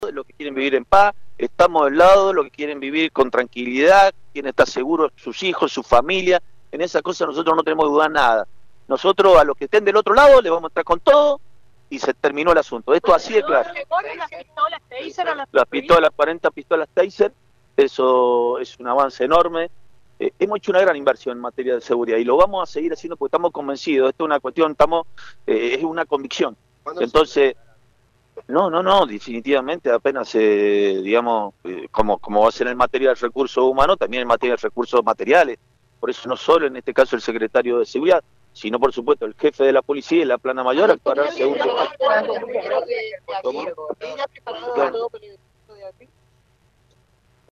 El Gobernador encabezó un acto con presentación de movilidades, equipamiento y oficialización de nombramientos para la Policía de San Juan.
Aquí la palabra del Gobernador Orrego: